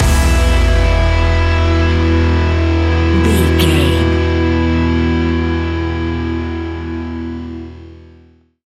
Ionian/Major
hard rock
instrumentals